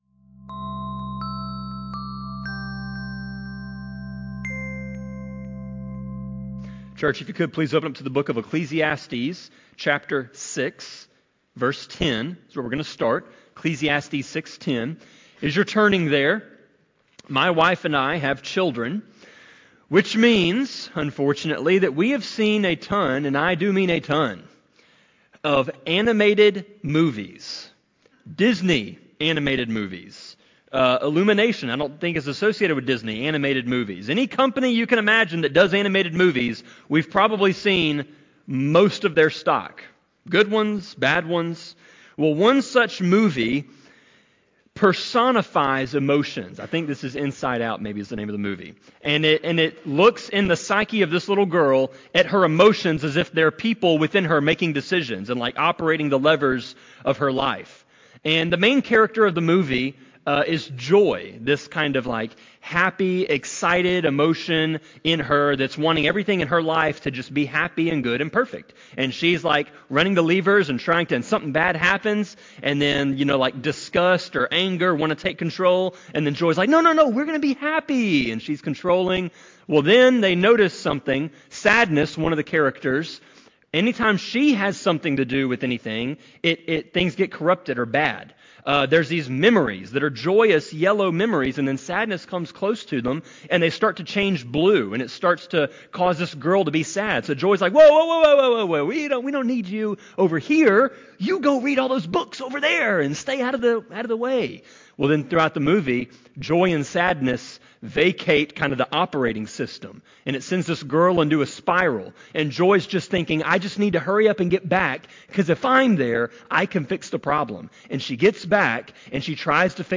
Sermon-25.3.23-CD.mp3